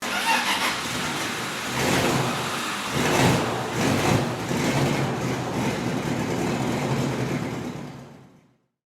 Free SFX sound effect: Tractor Rev. Download MP3 for free, royalty-free, commercial use included.
Tractor Rev
Tractor Rev.mp3